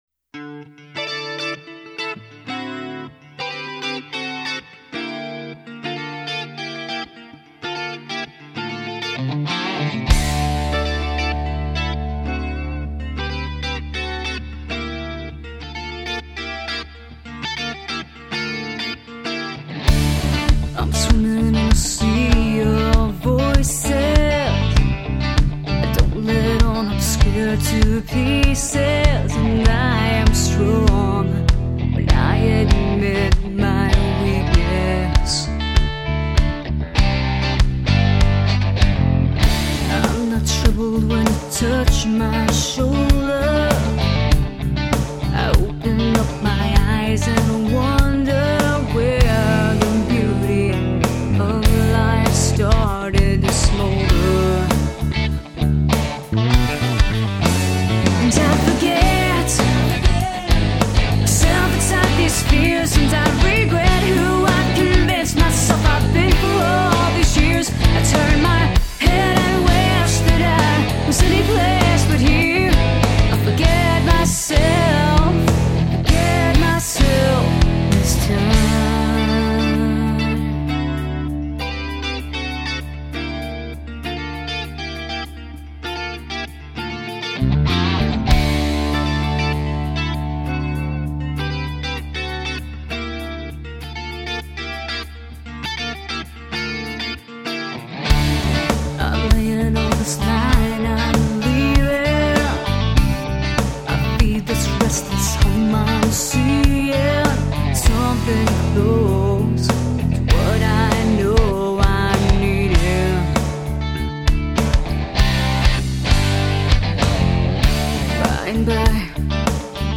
If you're looking for great catchy vocals and back-up vocals
romantic melodies
opened by soft keyboards